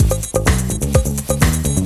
TECHNO125BPM 18.wav